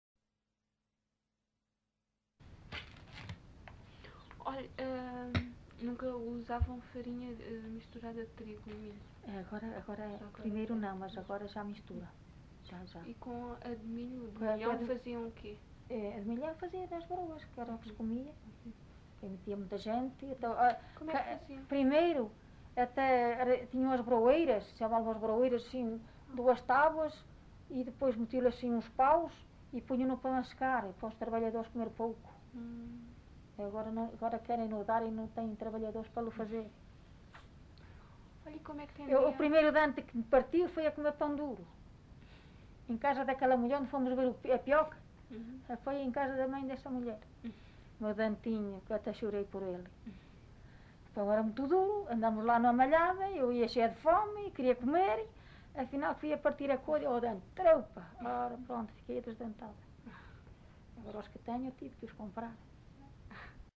LocalidadePerafita (Alijó, Vila Real)